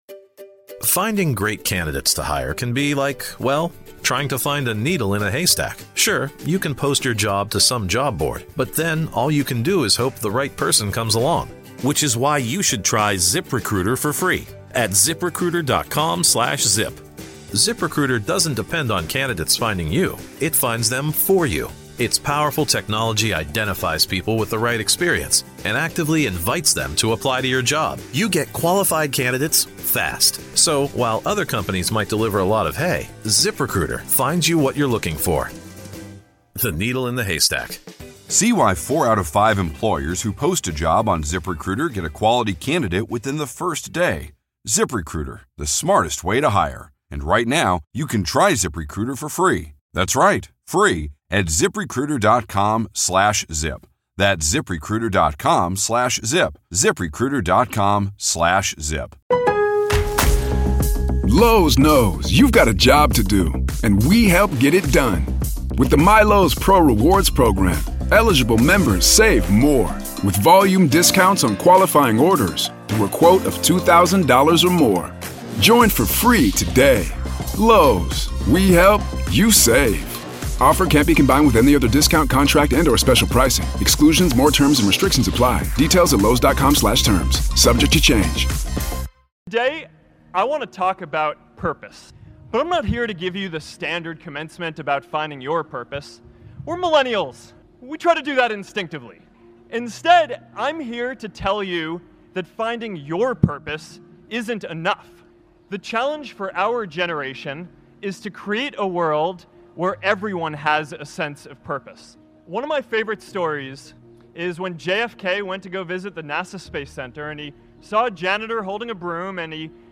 Mark Zuckerberg - Finding your purpose isn't enough anymore motivational speech